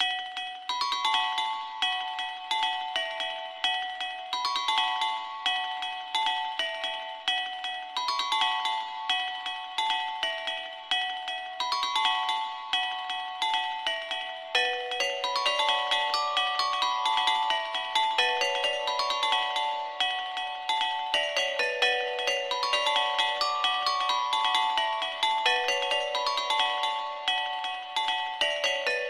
Tag: 66 bpm Chill Out Loops Synth Loops 2.45 MB wav Key : D